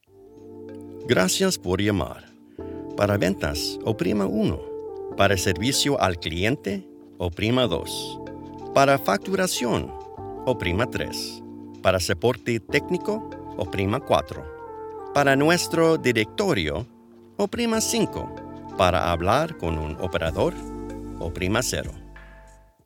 Telephony & IVR Voiceover
Warm. Clear. Built to Make Every Caller Feel Taken Care Of.
His delivery is clear and articulate without ever sounding robotic, helping callers stay engaged instead of frustrated.
Telephony & IVR Demos